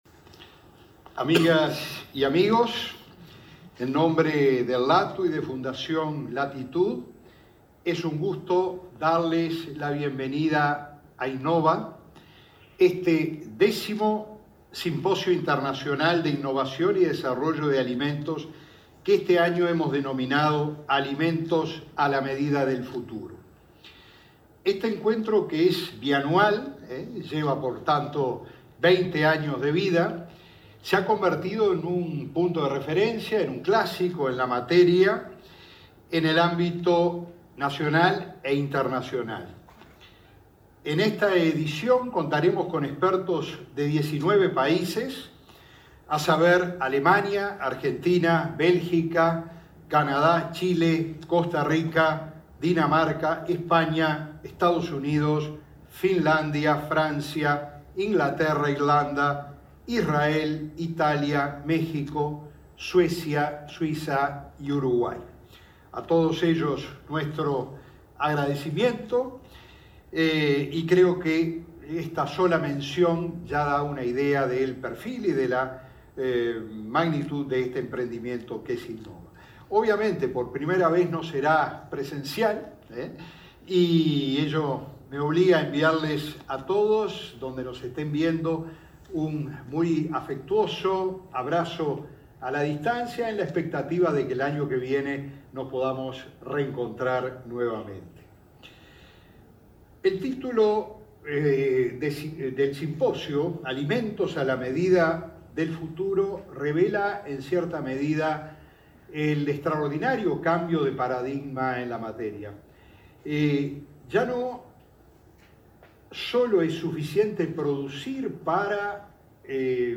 Palabras de autoridades en acto Innova Uruguay
Palabras de autoridades en acto Innova Uruguay 27/09/2021 Compartir Facebook Twitter Copiar enlace WhatsApp LinkedIn Este lunes 27, el presidente del LATU, Ruperto Long; la directora de Industrias, Susana Pecoy; el ministro de Ganadería, Fernando Mattos, y su par de Educación y Cultura, Pablo da Silveira, participaron del 10.° Simposio Internacional de Innovación y Desarrollo de Alimentos.